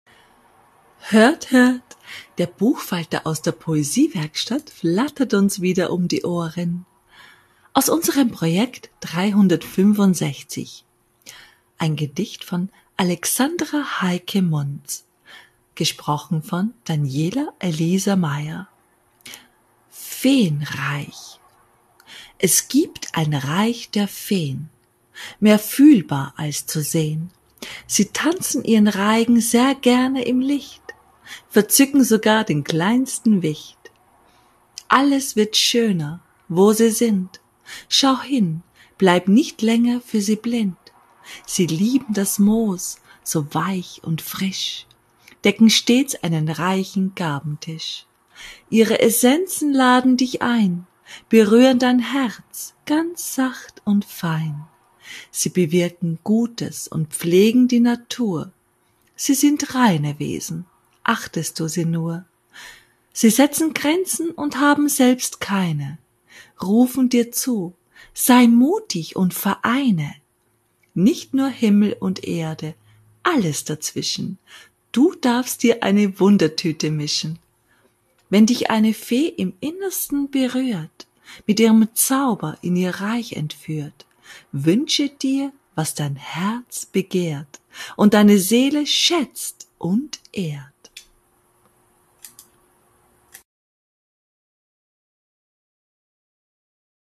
Das eine oder andere wird in diesem Podcast vorgestellt werden - als Hörversion des geschriebenen Textes.